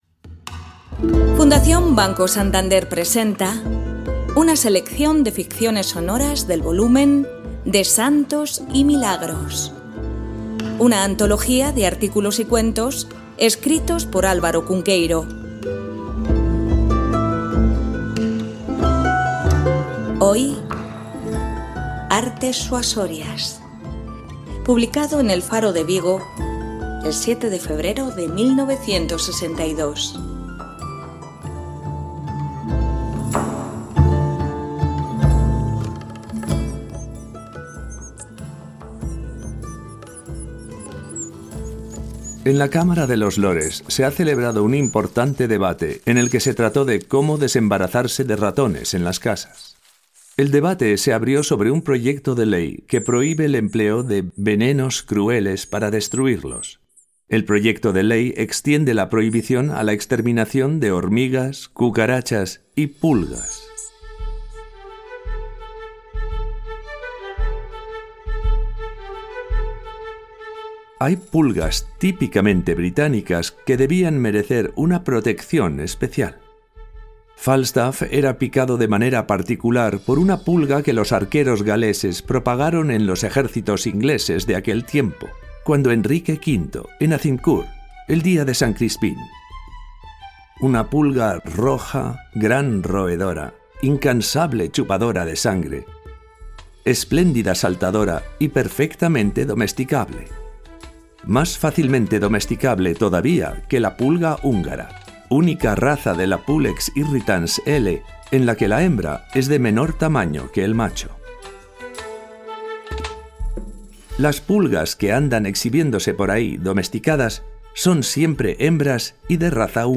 Ficciones sonoras